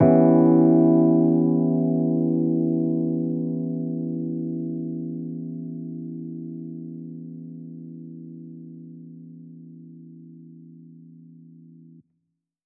JK_ElPiano2_Chord-Em6.wav